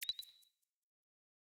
song-ping-variation-3.wav